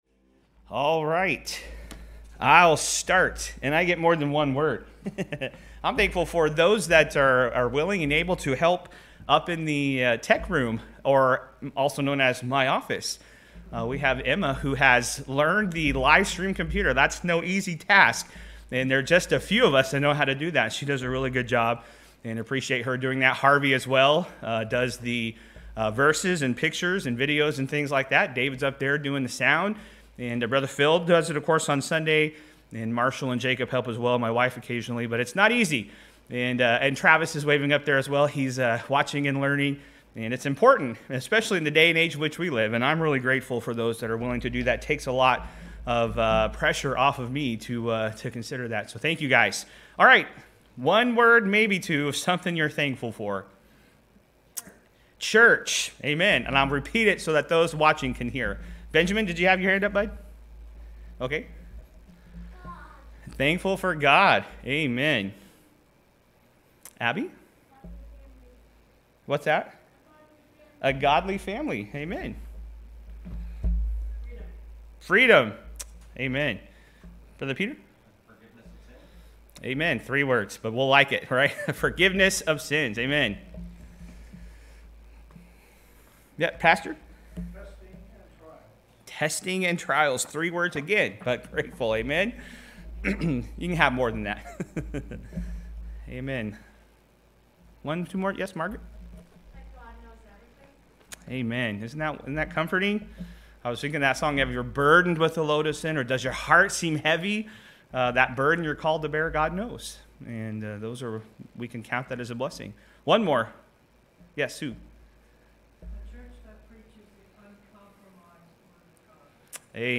Sermons | New Testament Baptist Church